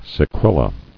[se·quel·a]